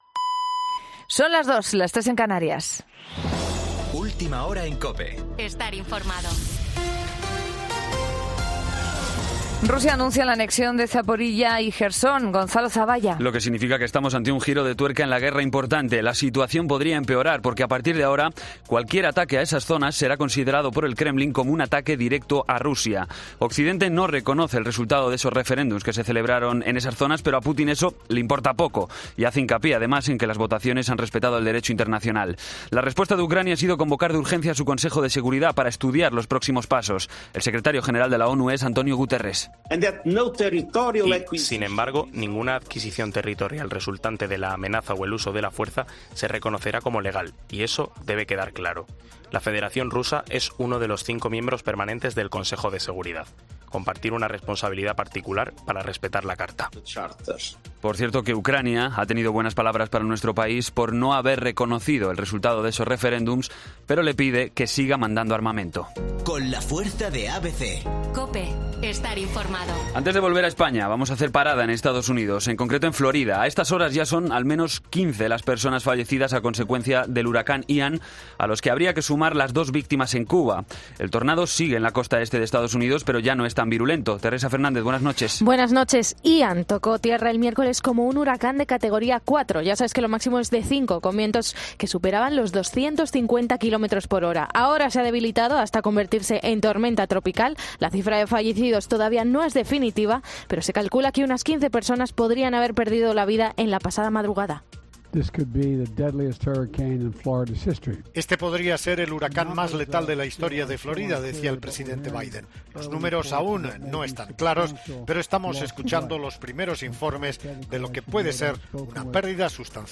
Boletín de noticias COPE del 30 de septiembre a las 02:00 hora
AUDIO: Actualización de noticias Herrera en COPE